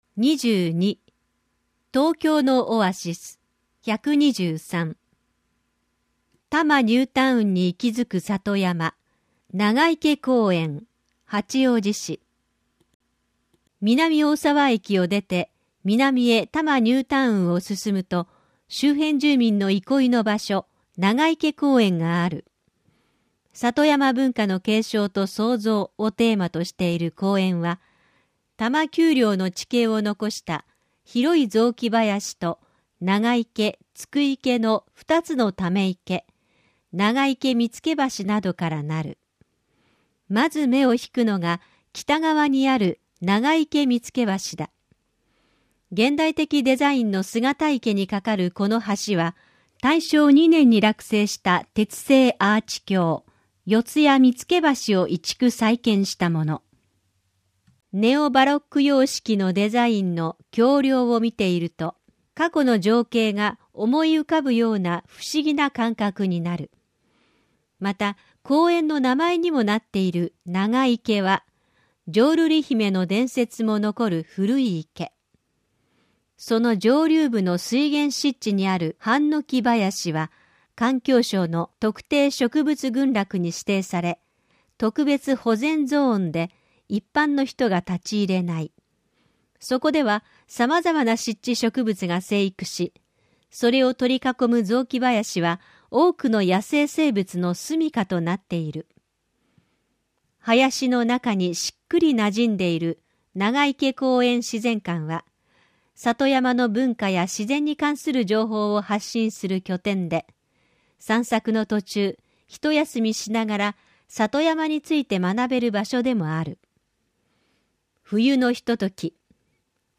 「広報東京都音声版」は、視覚に障害のある方を対象に「広報東京都」の記事を再編集し、音声にしたものです。